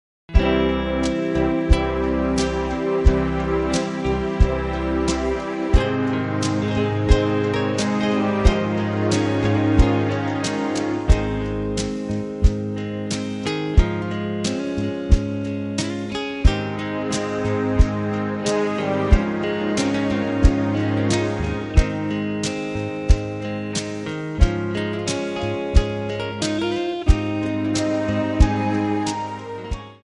Backing track files: 1960s (842)
Buy Without Backing Vocals